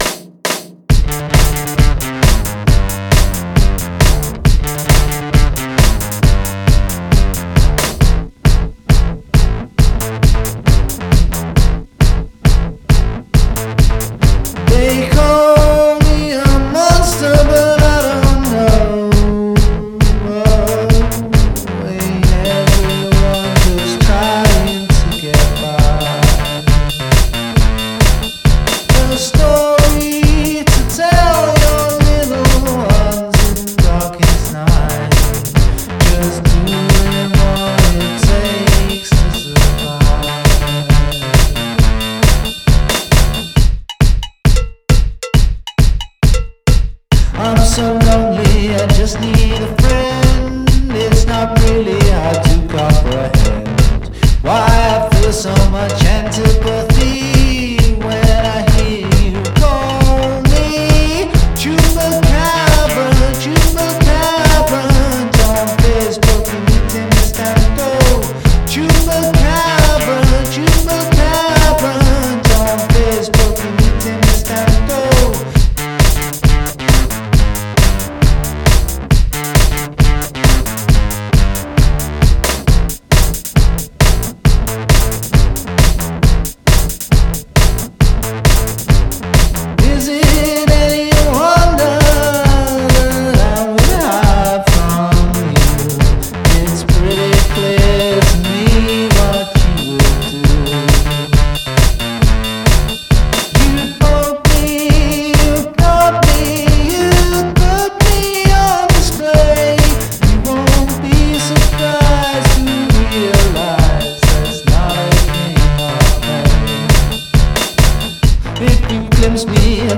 Very danceable.